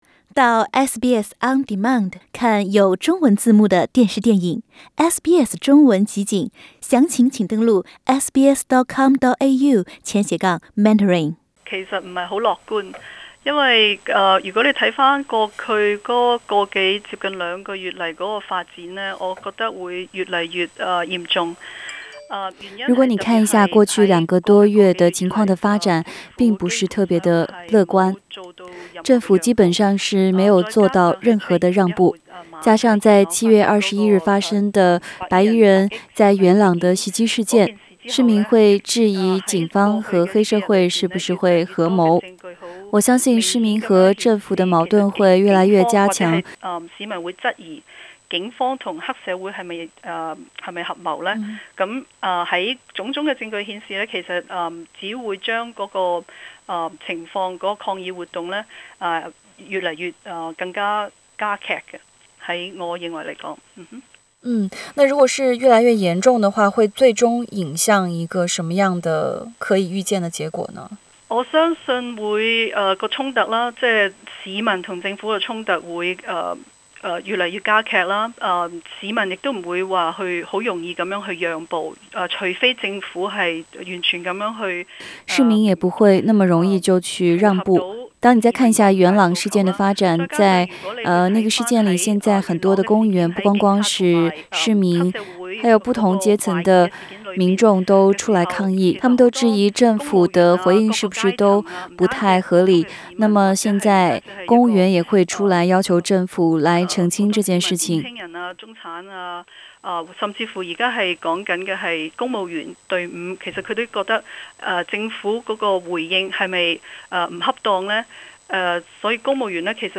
以上寀訪為嘉賓觀點，不代表本台立場。